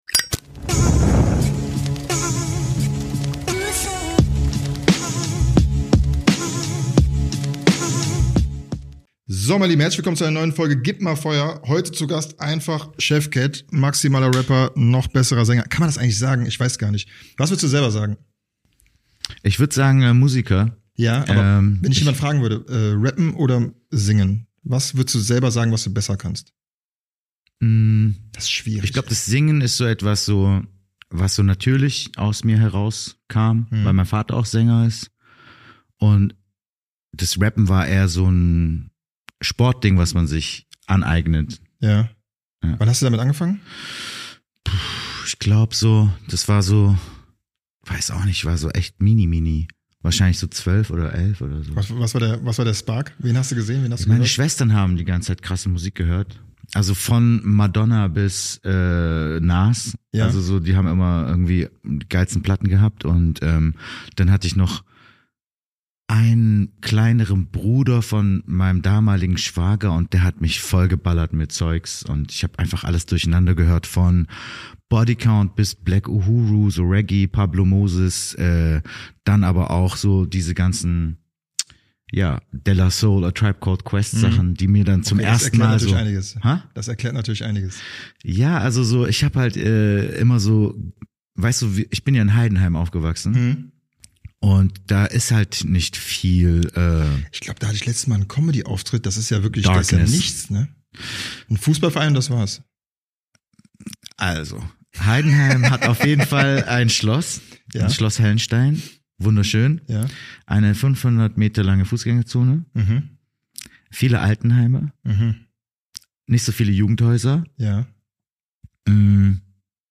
Einer der vielseitigsten Rapper des Landes war bei uns zu Gast und es wurde ordentlich gefachsimpelt, performed, gelachr und aus’m Nähkästchen geplaudert.